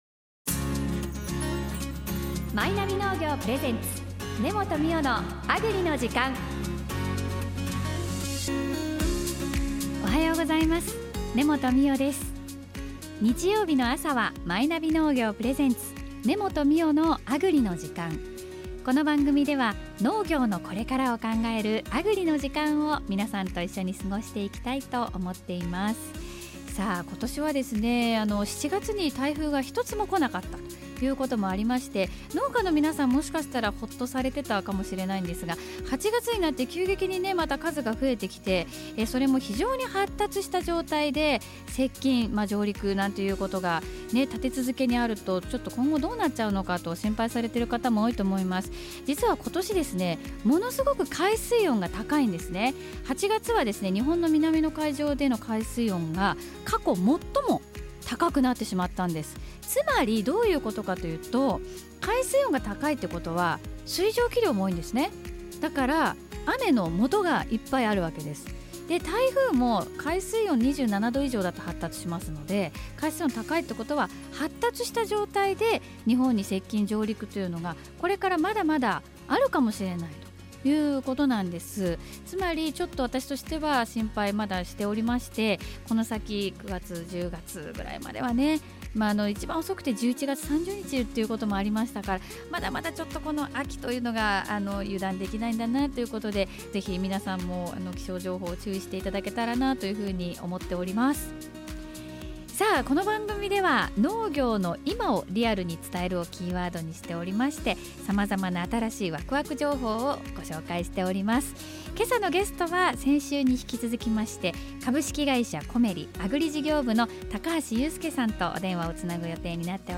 様々な分野で「農業の今」を支えている方々をゲストにお迎えし、「農業のこれから」を考える15分間の